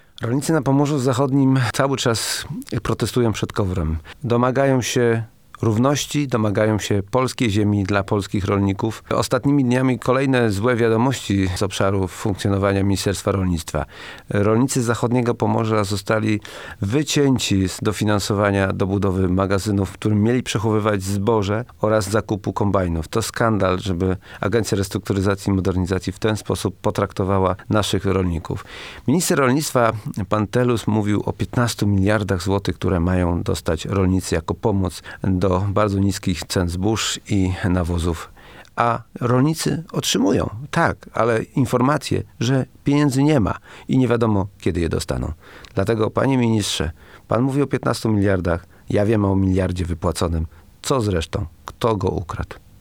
Zachodniopomorscy rolnicy nie otrzymali obiecanego dofinansowania do budowy magazynów zbożowych i zakupu kombajnów. O działaniach Agencji Restrukturyzacji i Modernizacji Rolnictwa mówi poseł Polskiego Stronnictwa Ludowego Jarosław Rzepa.